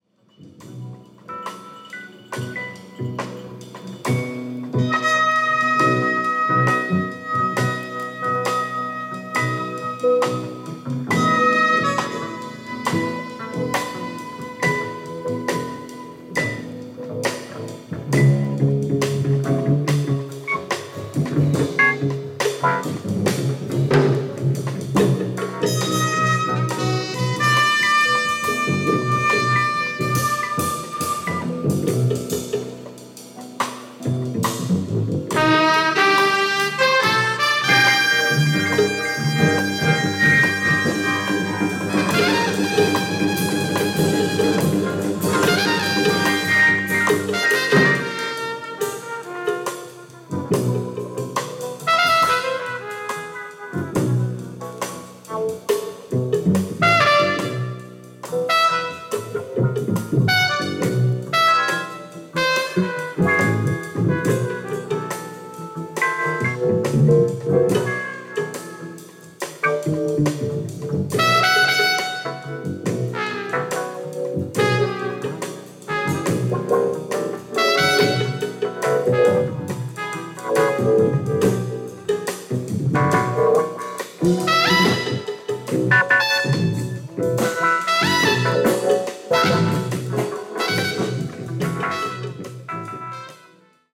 Bass
Electric Piano
Percussion